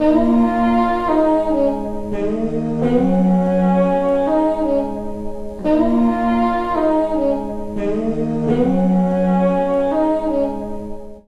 03J-SAX-.A-L.wav